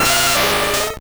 Cri de Rhinoféros dans Pokémon Or et Argent.